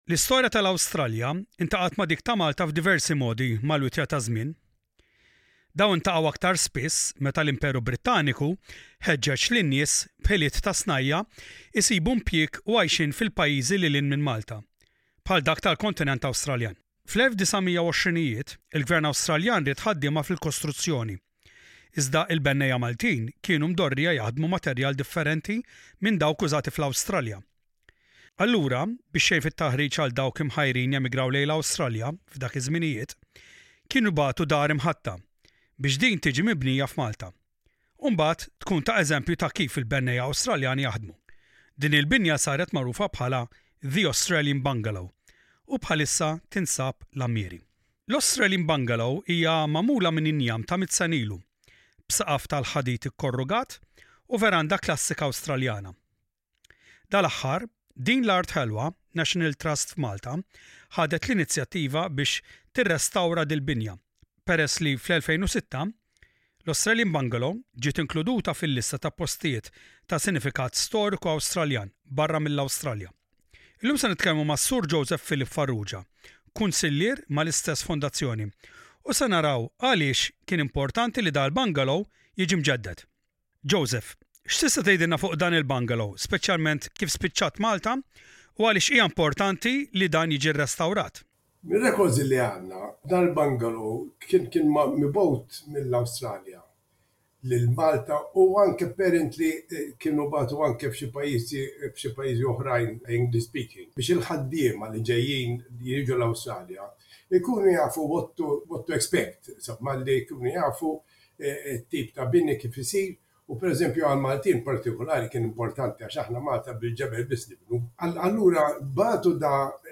INTERVISTA Australian Bungolow Online.mp3 Share